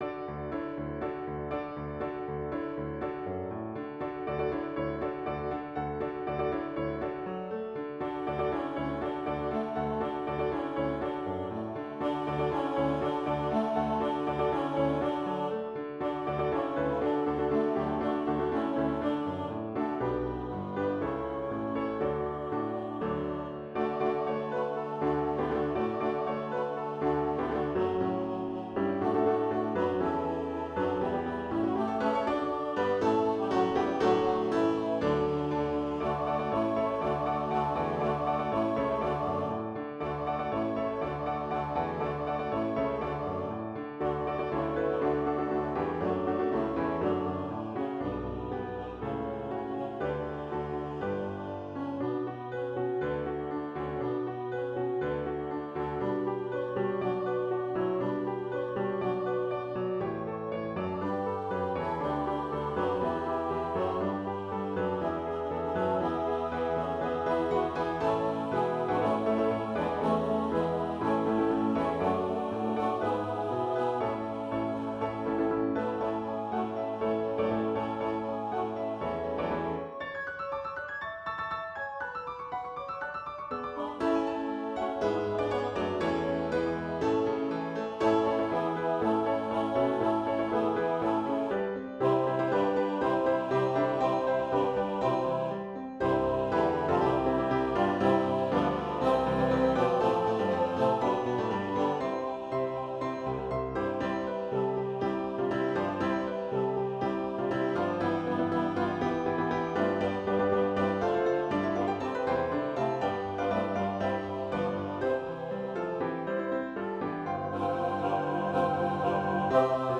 Christian, Gospel, Sacred, Christmas.